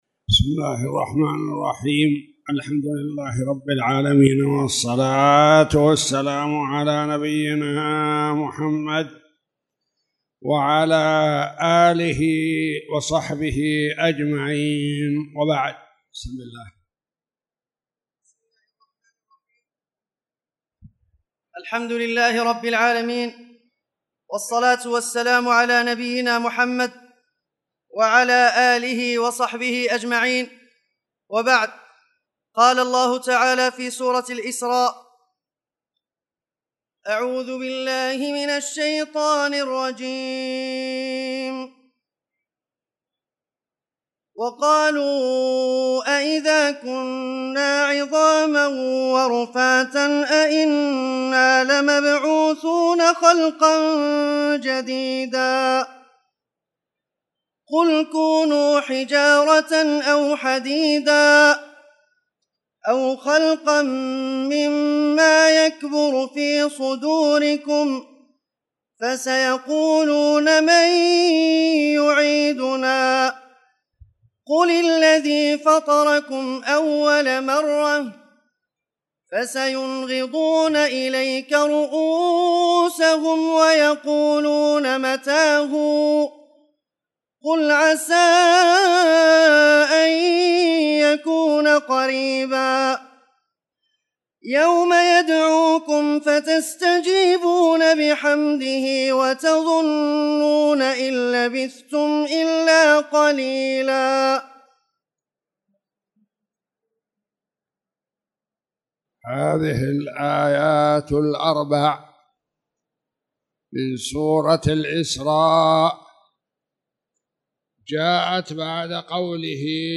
تاريخ النشر ١٩ شعبان ١٤٣٧ هـ المكان: المسجد الحرام الشيخ